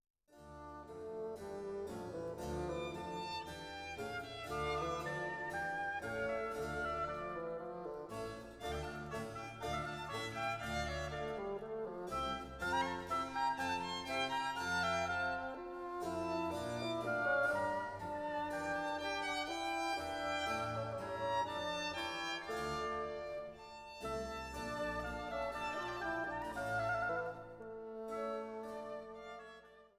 für Trompete, Oboe und Basso continuo: Grave